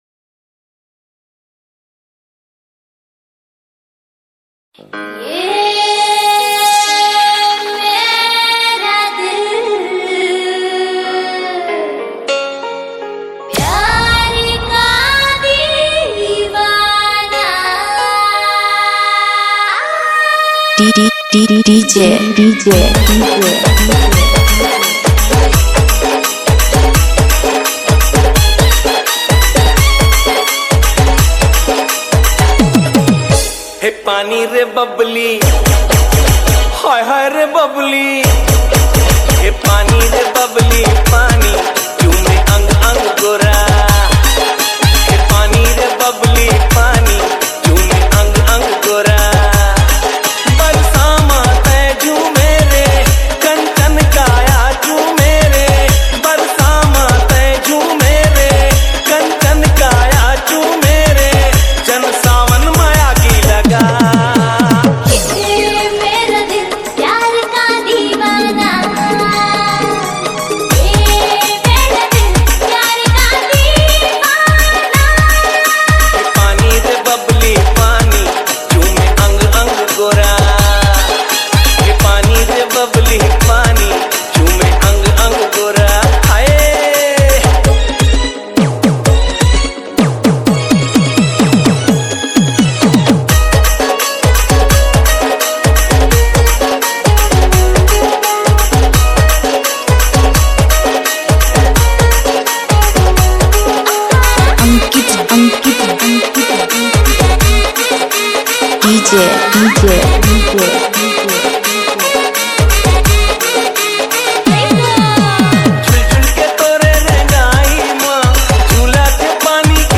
CG ROMANTIC DJ REMIX